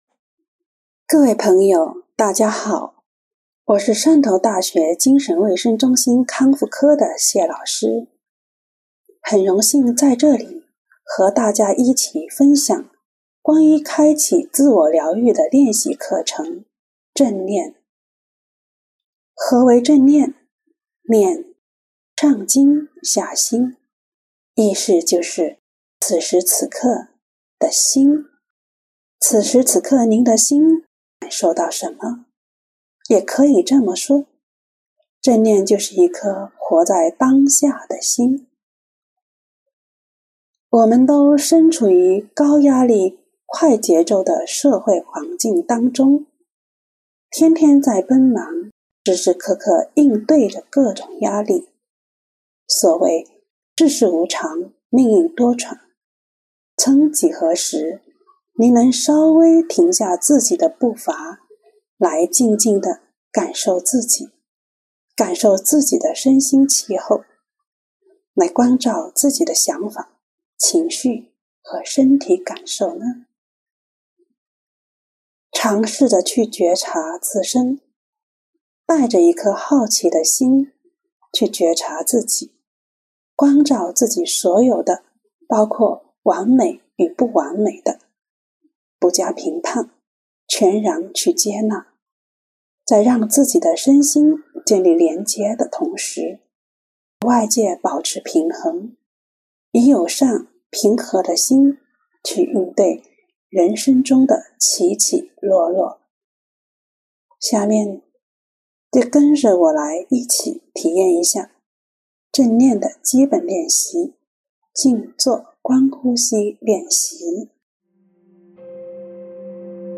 下面，跟着我的引导一起体验一下正念的基础练习：静观呼吸练习。